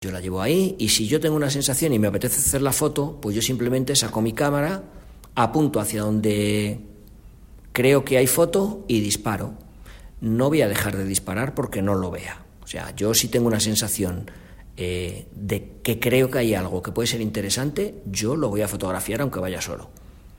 De hablar pausado y tranquilo